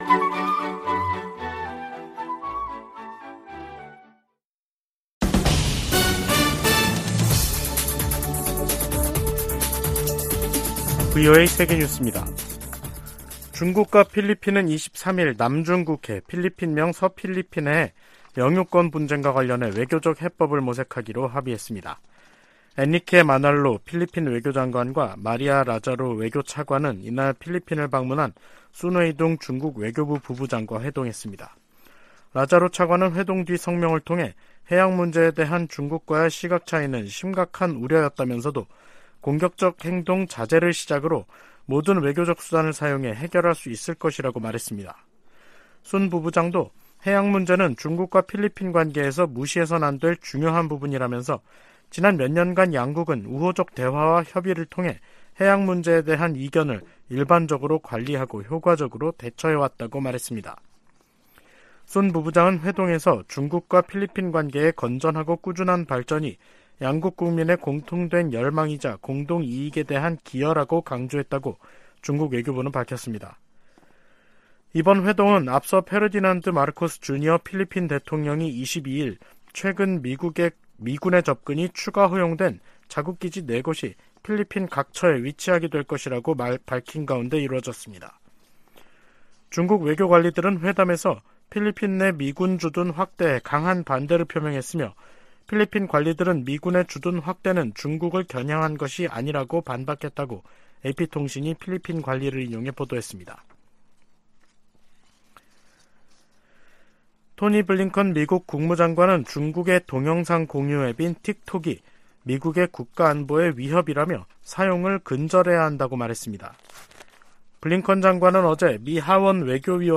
VOA 한국어 간판 뉴스 프로그램 '뉴스 투데이', 2023년 3월 24일 3부 방송입니다. 북한이 '핵 무인 수중 공격정' 수중 폭발시험을 진행했다고 대외관영 매체들이 보도했습니다. 로이드 오스틴 미 국방장관은 북한을 지속적인 위협으로 규정하며 인도태평양 지역에서 방위태세를 강화하고 훈련 범위와 규모도 확대하고 있다고 밝혔습니다. 윤석열 한국 대통령은 '서해 수호의 날' 기념사에서 북한의 무모한 도발에는 대가를 치르게 하겠다고 강조했습니다.